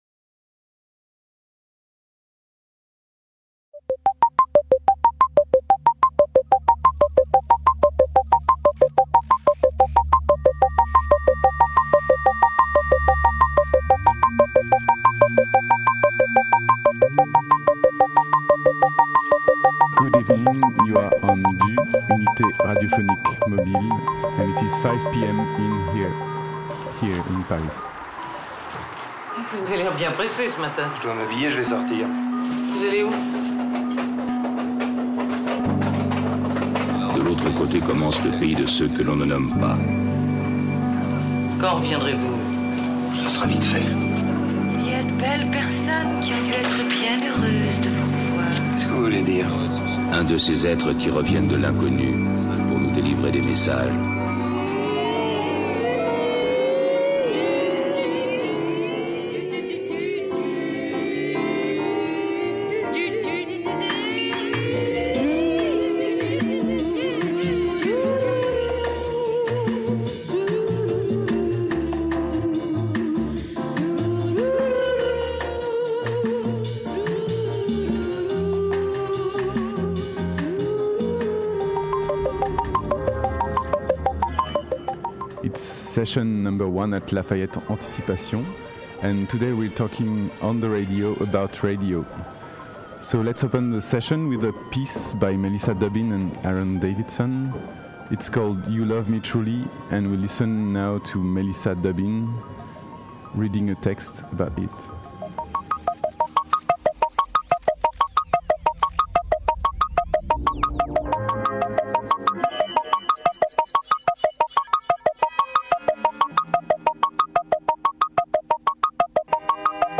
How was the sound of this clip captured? On the occassion of the exhibition"Joining Forces with the Unknown" October 11-23, 2016 at 16 rue Debelleyme 75003 Paris, *DUUU was a site-specific radio station offering daily broadcasts from the studio within the exhibition space.